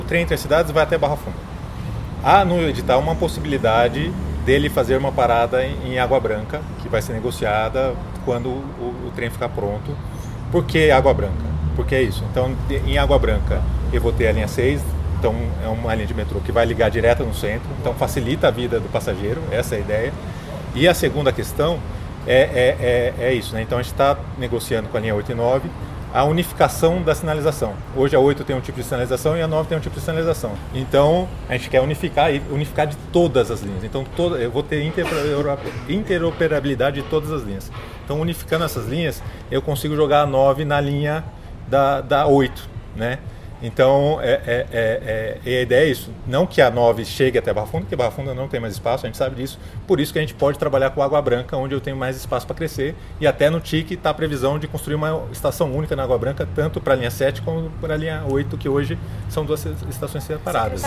Em evento de chegada do Tatuzão Sul à estação Perdizes da linha 6-Laranja, na manhã deste sábado, 30 de agosto de 2023, o secretário de Parcerias em Investimentos, Rafael Benini, disse em entrevista coletiva que o terminal do TIC continuará sendo Barra Funda, como no projeto original.